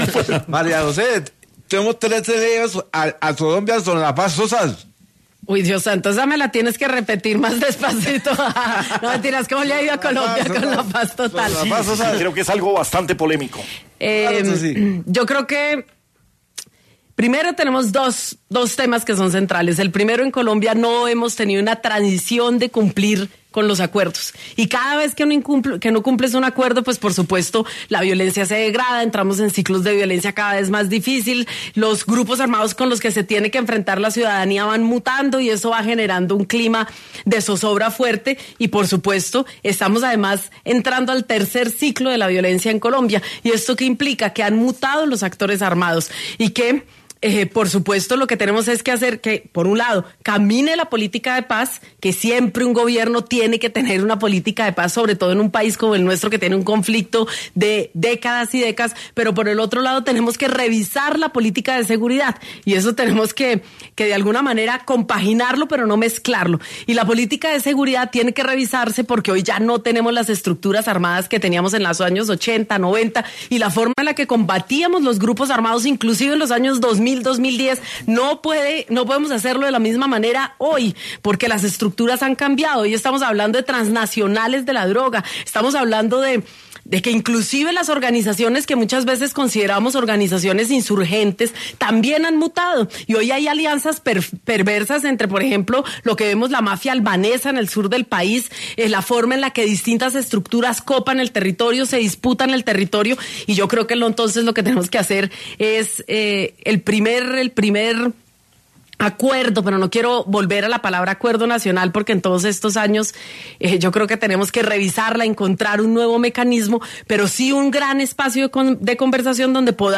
En los micrófonos de Sin Anestesia’ de La Luciérnaga y Red + Noticias, la senadora por el Pacto Histórico María José Pizarro expresó su preocupación por el estado actual del proceso de paz en Colombia.